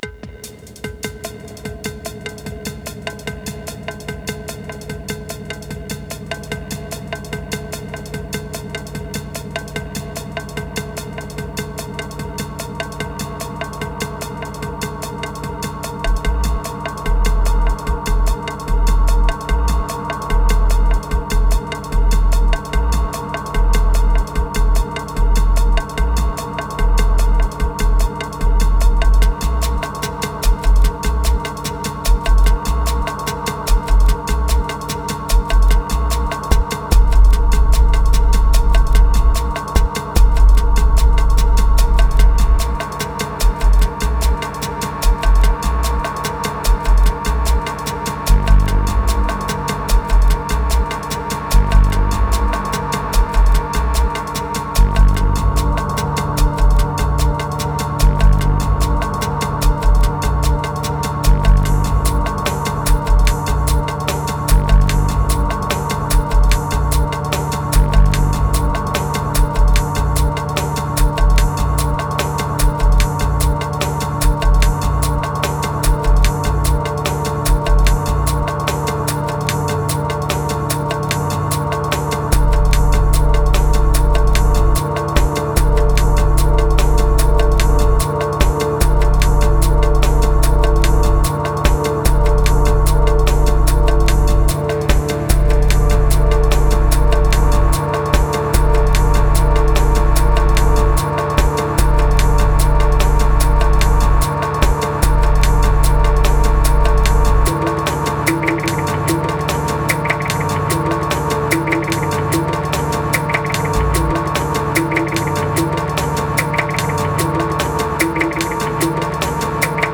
805📈 - 39%🤔 - 78BPM🔊 - 2020-10-31📅 - 232🌟
Relief Riddim